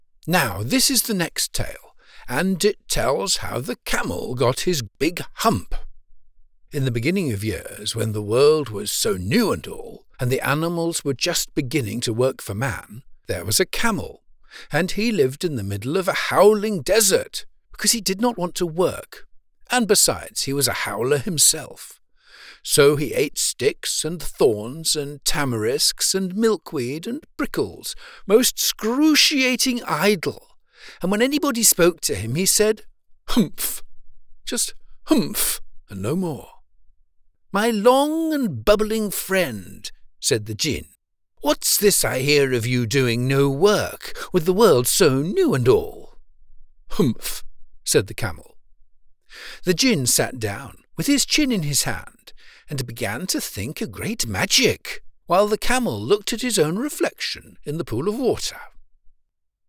British Audiobook Narrator: